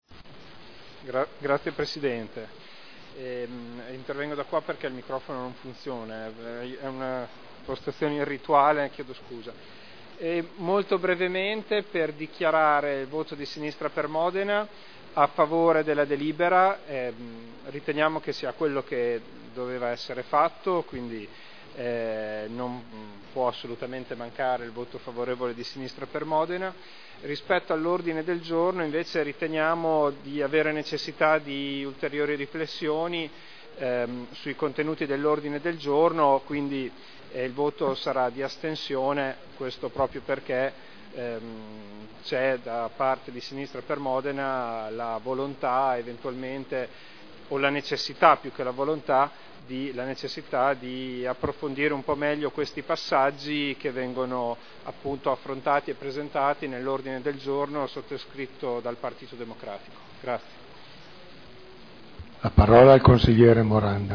Seduta del 17/01/2011. Regolamento comunale del mercato coperto quotidiano di generi alimentari denominato “Mercato Albinelli” – Approvazione modifiche Dichiarazione di voto